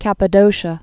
(kăpə-dōshə, -shē-ə)